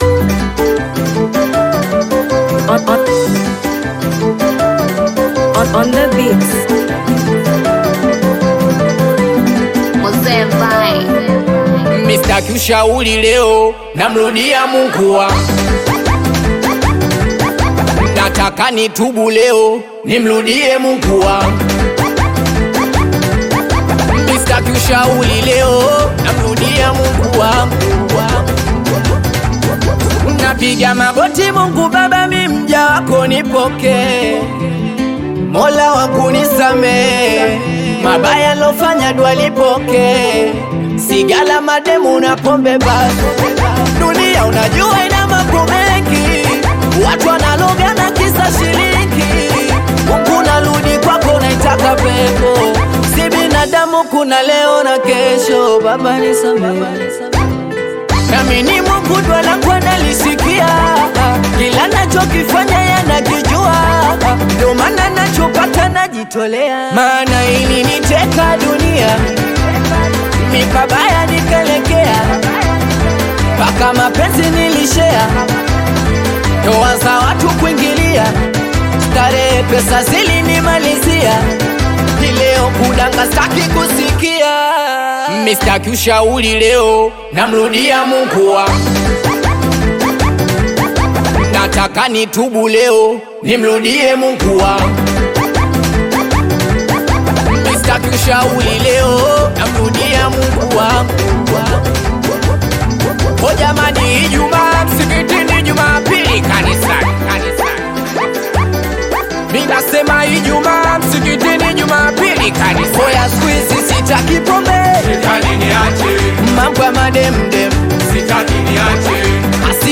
Singeli music track
Tanzanian Bongo Flava Singeli